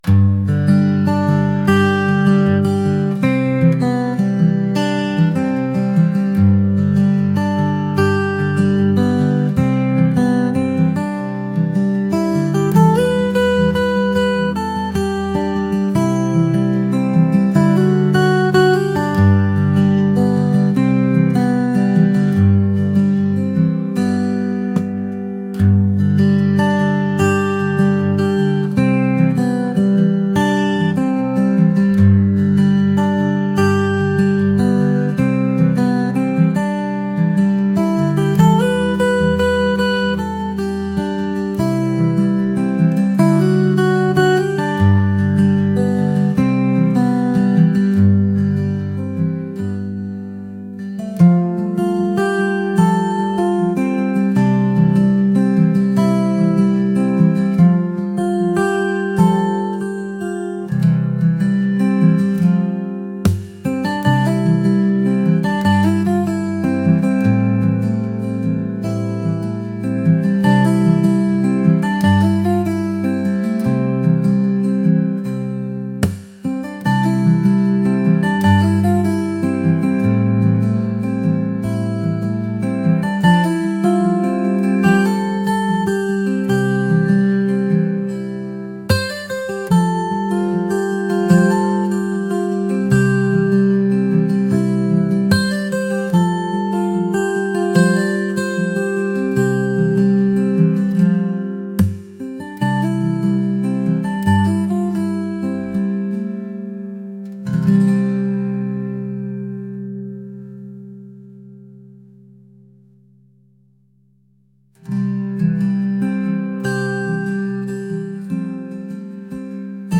folk | acoustic | mellow